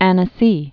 (ănə-sē, än-sē)